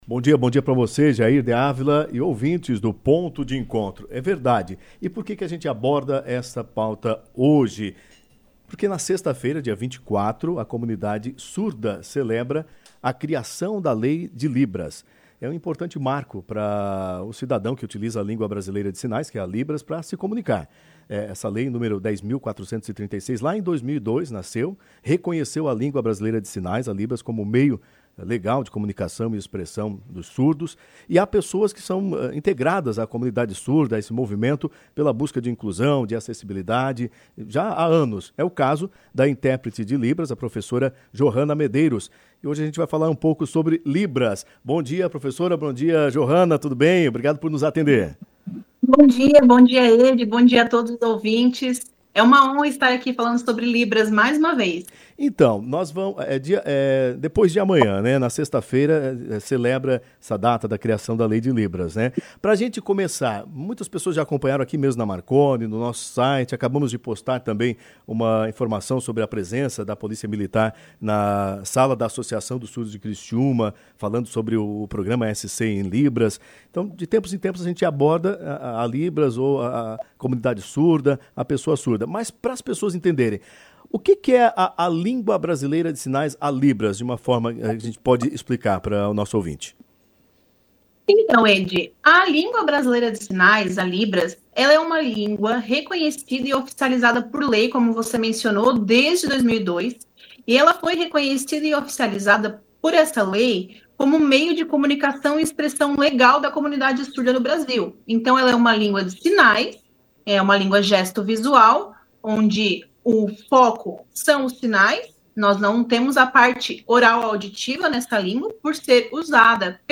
Importância da Língua Brasileira de Sinais foi destacada em entrevista